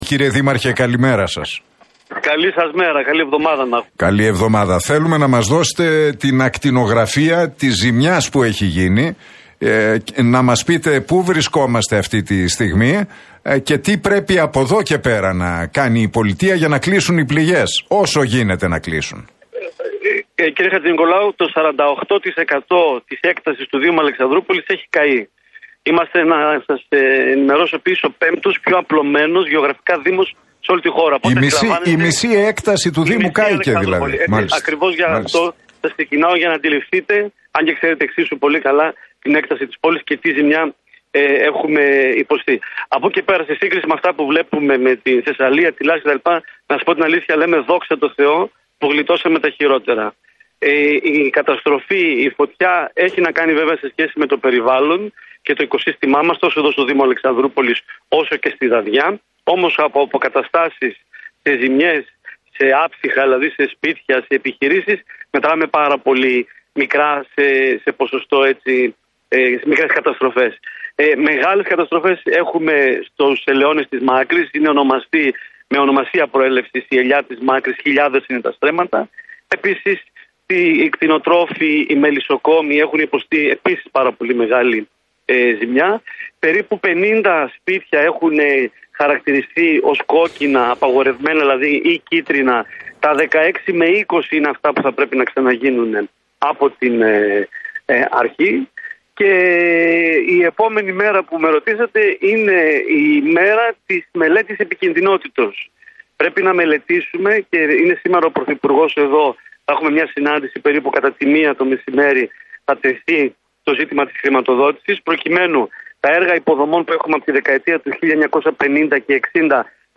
Η μάχη για να αναγεννηθεί ο Έβρος μετά τις φωτιές – Τι λένε στον Realfm 97,8 o αντιπεριφερειάρχης και ο Δήμαρχος Αλεξανδρούπολης